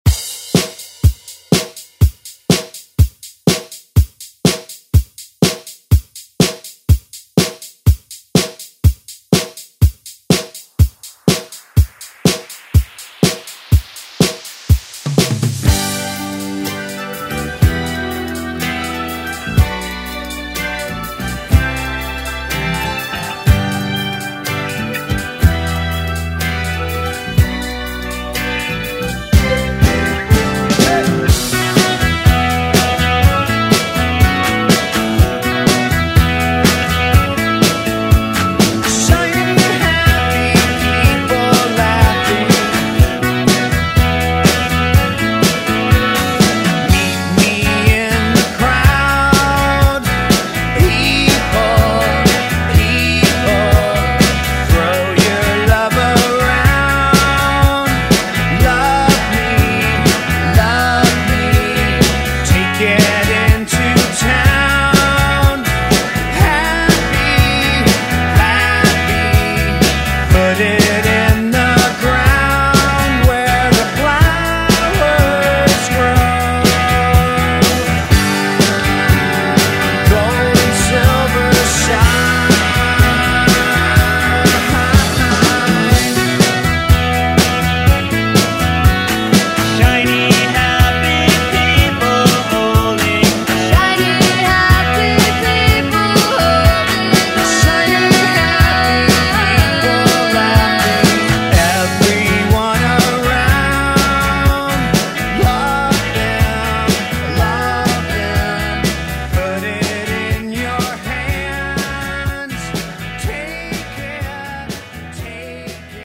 Electronic Pop Rock
123 bpm
BPM: 123 Time